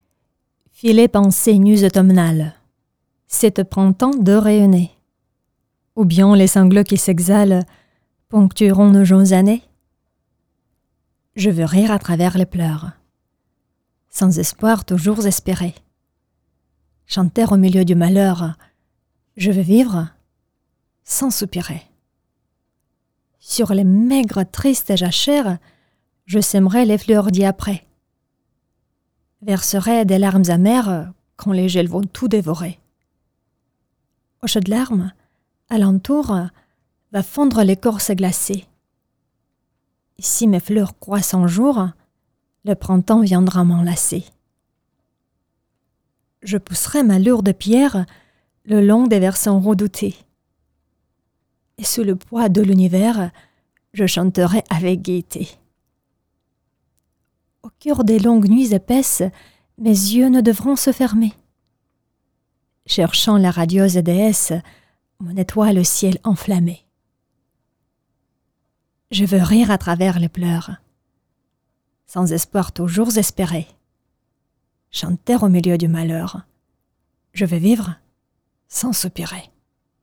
- Soprano